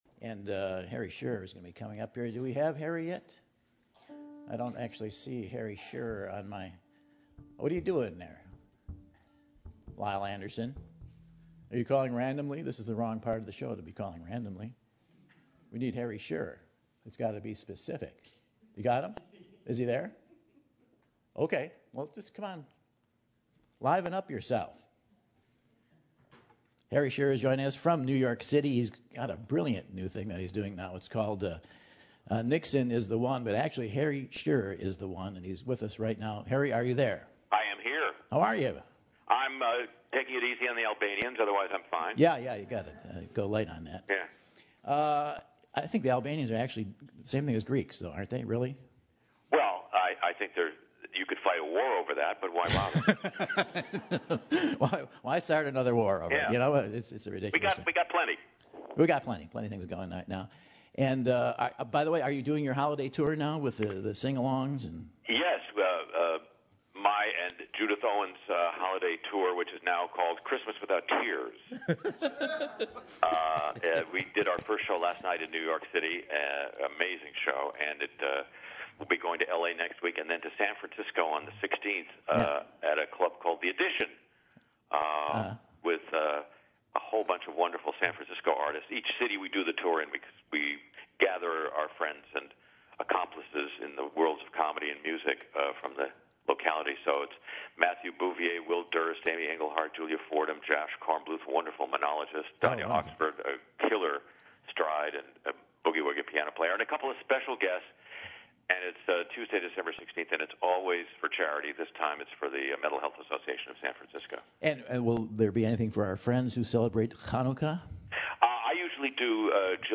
He calls in from New York to talk to Michael about his hilarious Nixon's the One!